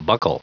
Prononciation du mot buccal en anglais (fichier audio)